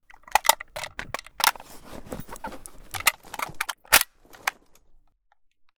bizon_reload.ogg